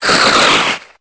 Cri de Stalgamin dans Pokémon Épée et Bouclier.